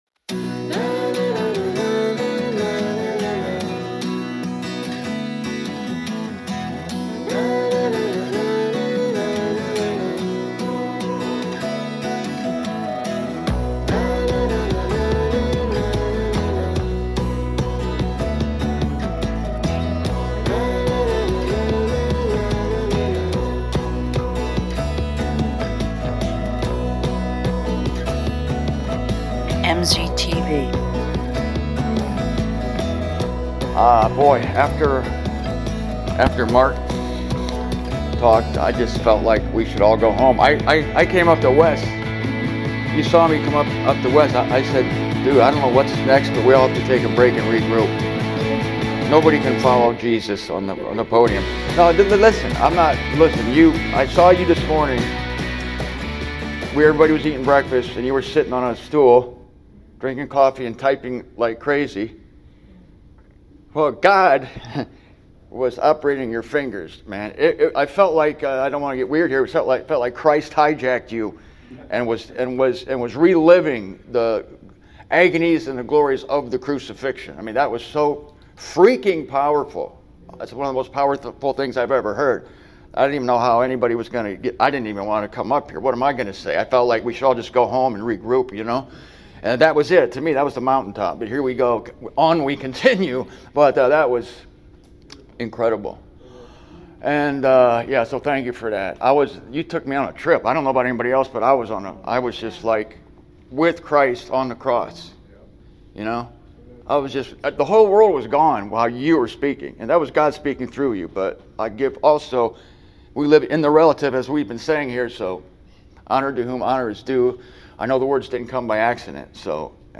This time I am delivering it from the Des Moines, IA conference from September of last year.